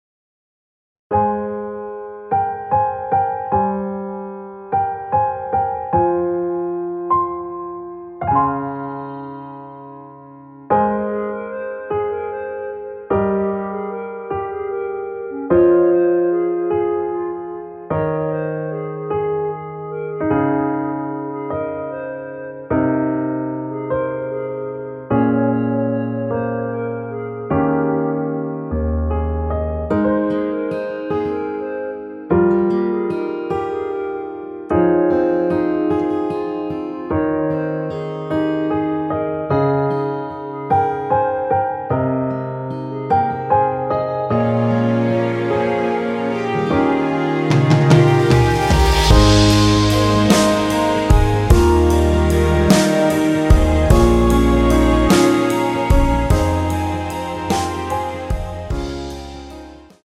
Ab
멜로디 MR이라고 합니다.
앞부분30초, 뒷부분30초씩 편집해서 올려 드리고 있습니다.
중간에 음이 끈어지고 다시 나오는 이유는